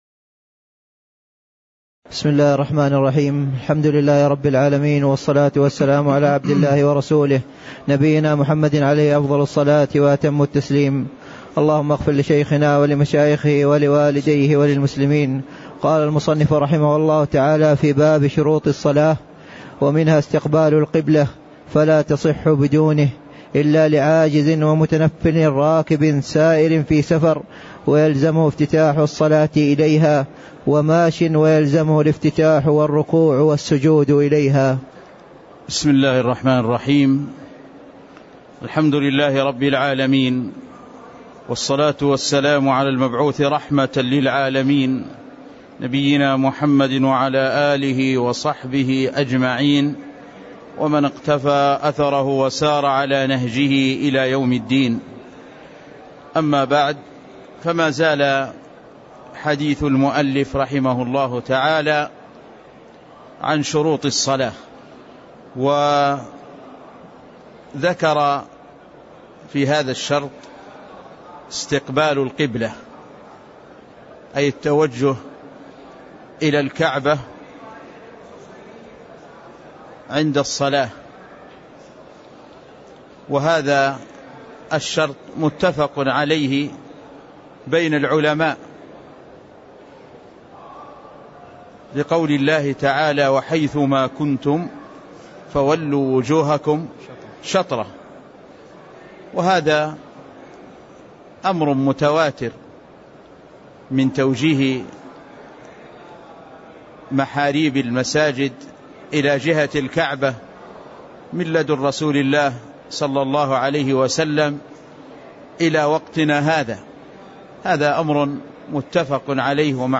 تاريخ النشر ٢١ رجب ١٤٣٥ هـ المكان: المسجد النبوي الشيخ